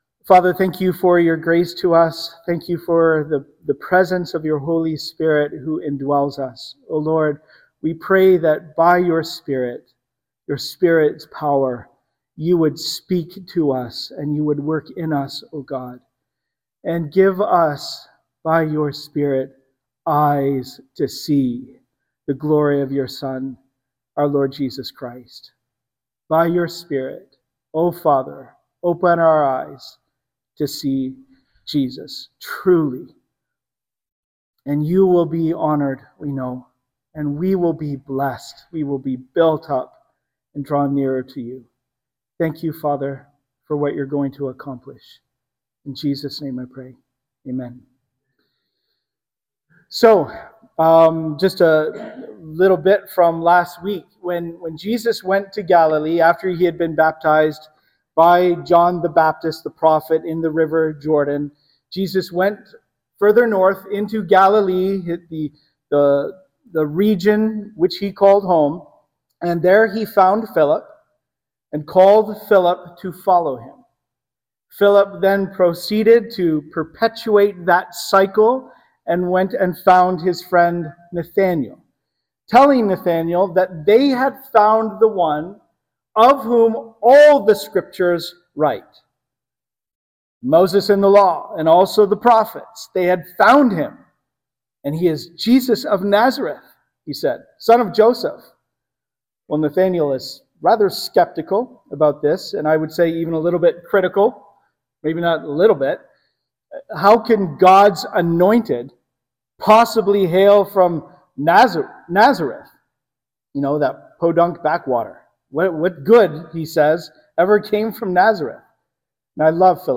Sermons - Cannington Baptist Church